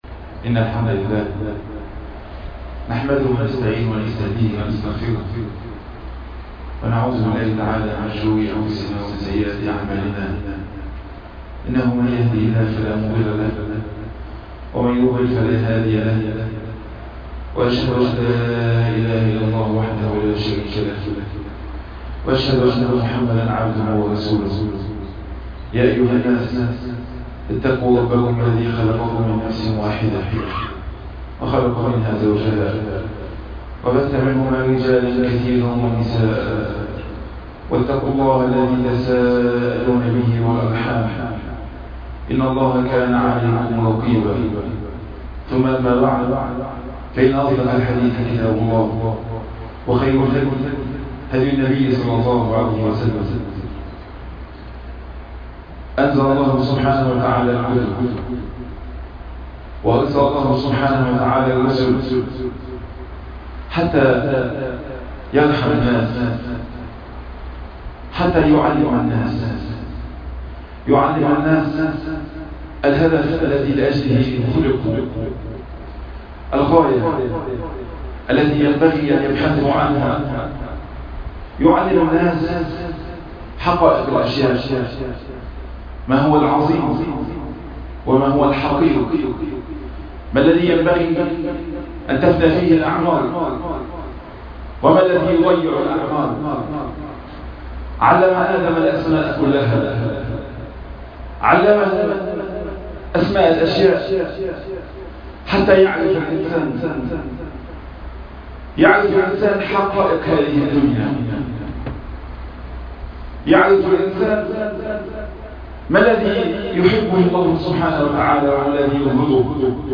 عنوان المادة منكم من يريد الدنيا ومنكم من يريد الآخرة - خطب الجمعة تاريخ التحميل السبت 11 يوليو 2020 مـ حجم المادة 14.94 ميجا بايت عدد الزيارات 708 زيارة عدد مرات الحفظ 249 مرة إستماع المادة حفظ المادة اضف تعليقك أرسل لصديق